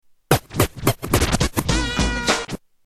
Turntable loop we2 107 BPM